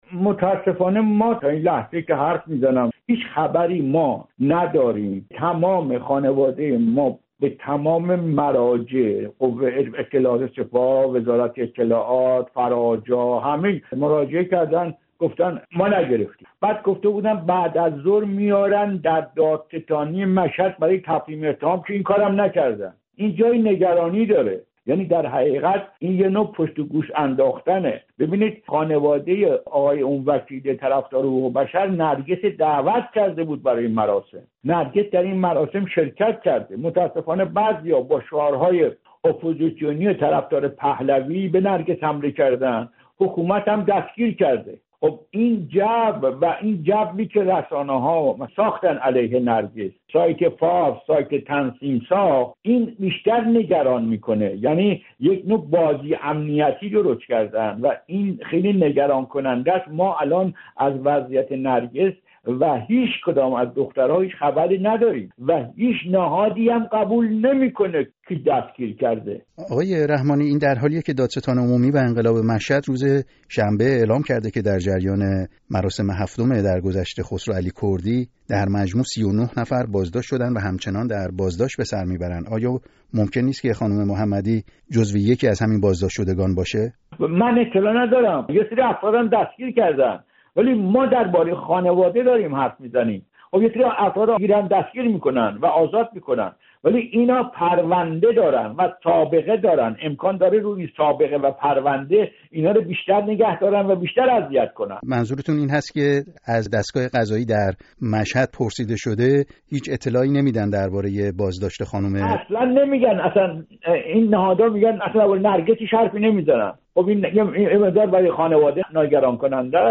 بی‌خبری از وضعیت نرگس محمدی در گفت‌وگو با تقی رحمانی